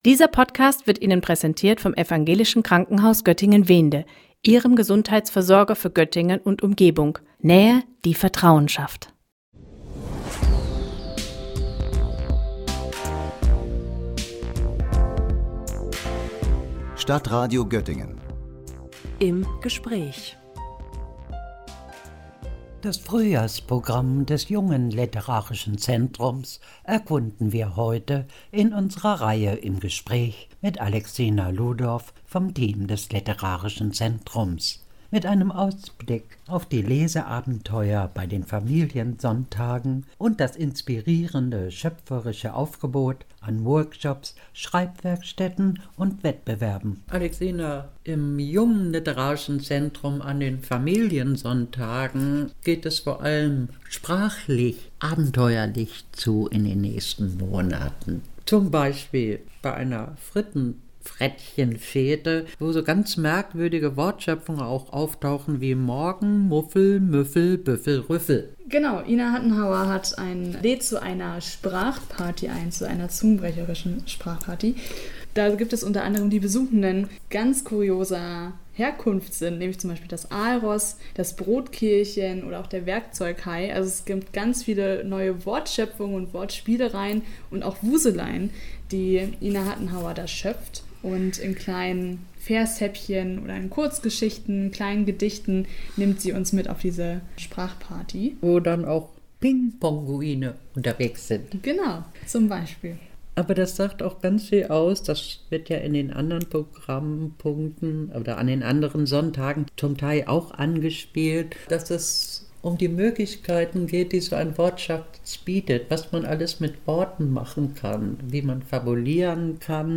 Im Gespräch: Ausblick auf das Programm des Jungen Literarischen Zentrums